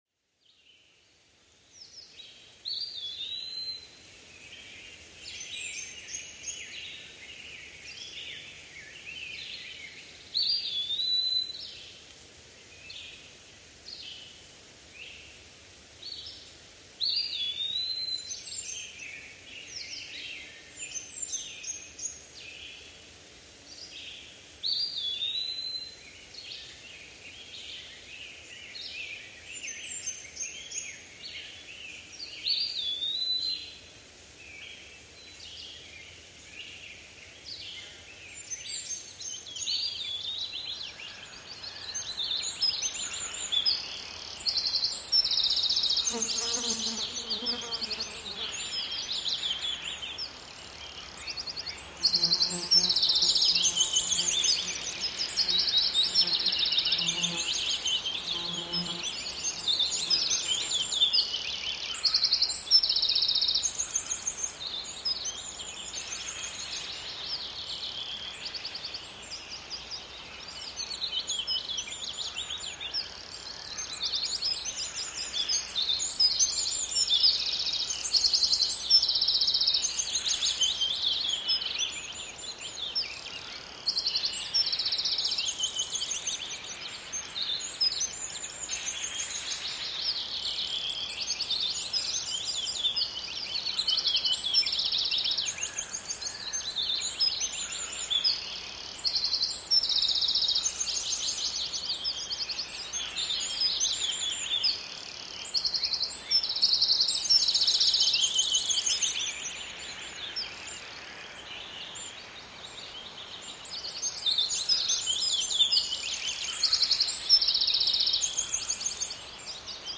Wind-down to the sounds of nature. The sunshine twirls in the green treetops, which rustle gently in the breeze. Birds twitter and chirp, and in the distance, you hear a peaceful babbling brook.
ambience-in-the-forest-en-audiokniha